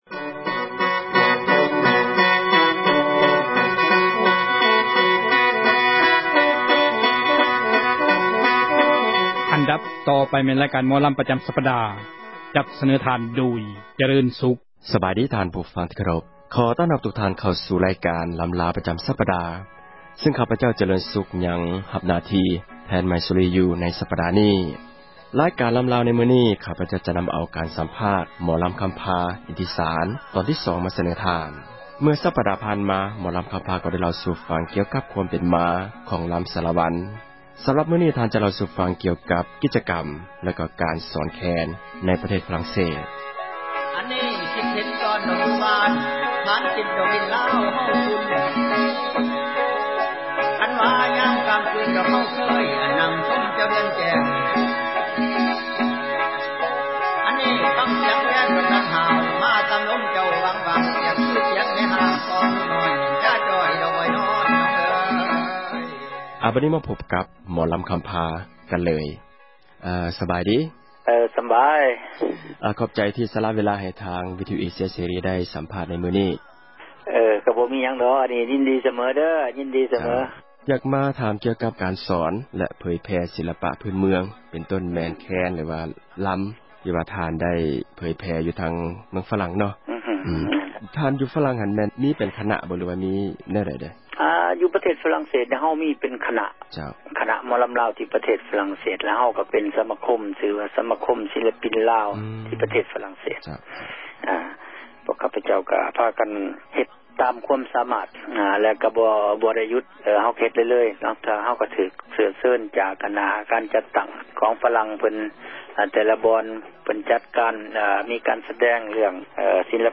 ການສັມພາດ
ລໍາລ່ອງ